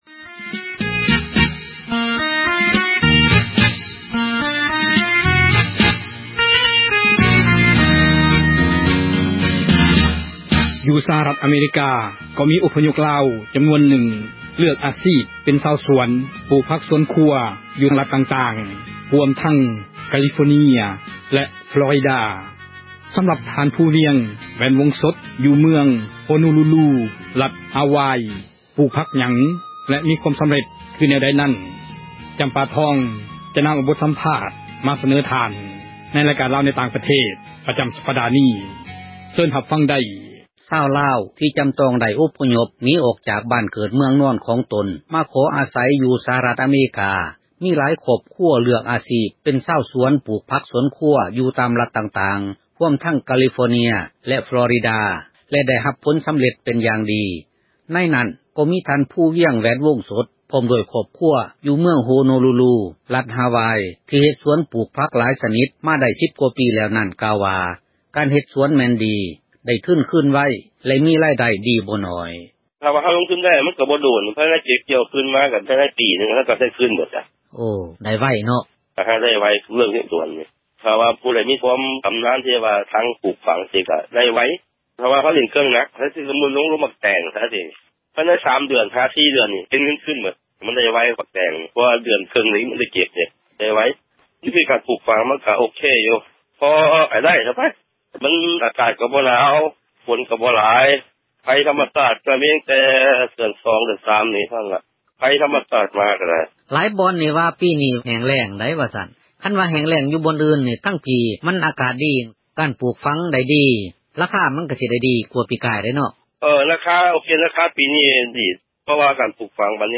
ການສໍາພາດ ຊາວສວນລາວ ທີ່ຣັດ Hawaii